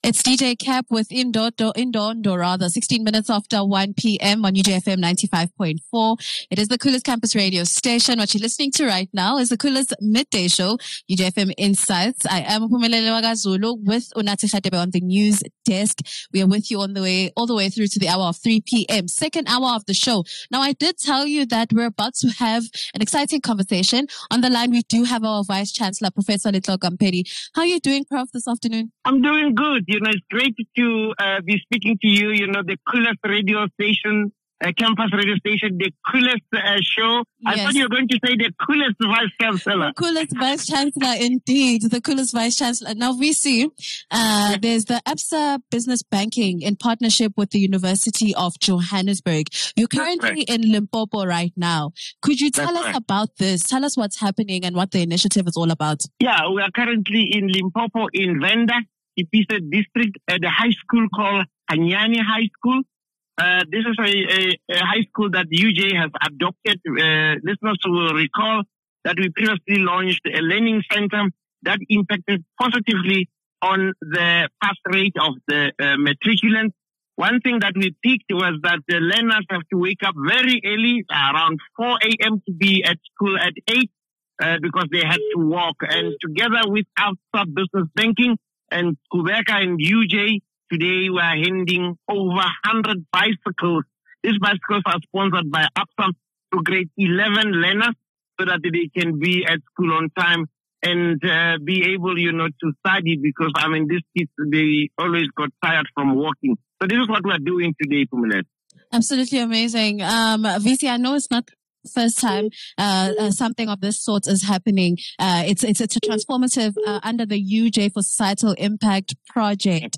In this episode, we sit down with the Vice-Chancellor of the University of Johannesburg, Professor Letlhokwa Mpedi, for an engaging conversation on impactful initiatives shaping education and society. Broadcasting live from Limpopo, Prof. Mpedi highlights UJ’s partnership with ABSA Business Banking, where 100 bicycles were donated to learners at Hanyani High School to ease their daily commute and improve academic success. He further reflects on UJ’s commitment to the UN Sustainable Development Goals, emphasizing the importance of sustainability, community impact, and preparing future leaders.